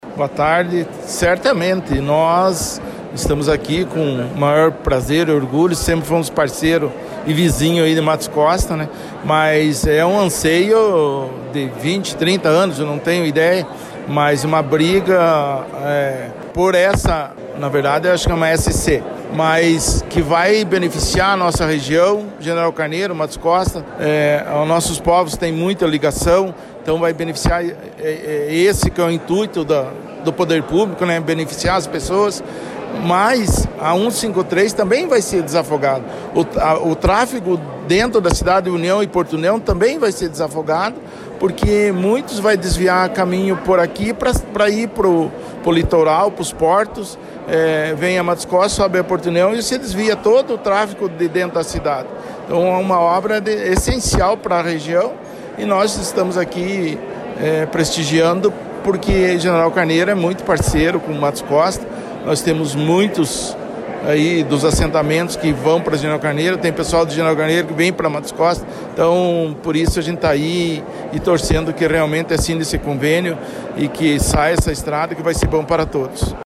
Também o prefeito de General Carneiro, Joel Ferreira, falou sobre o benefício dos recursos destinados para a melhoria da SC 462 que liga Matos Costa à BR 153 em General Carneiro.
PREFEITO-DE-GENERAL-CARNEIRO-JOEL-FERREIRA.mp3